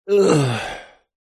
Звуки речи, голоса, пения
Мужской вздох, ох 1